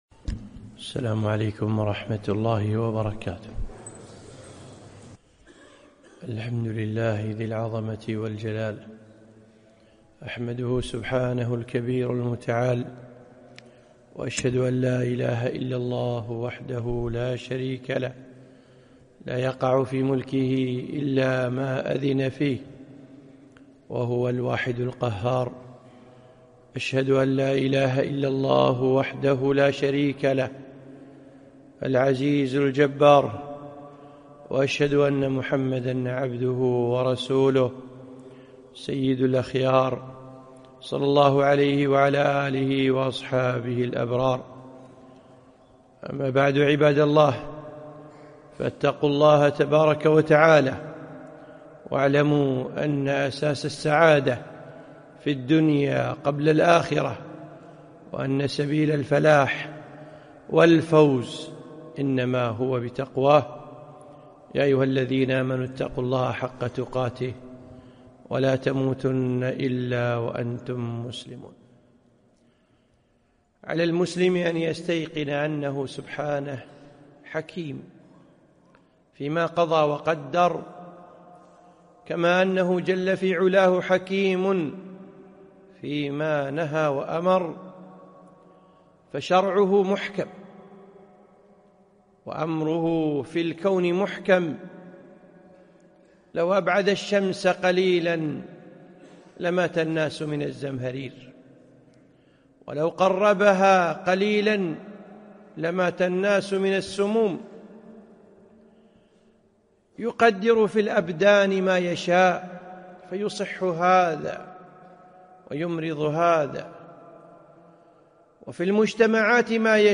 خطبة - الزلزلة الصغرى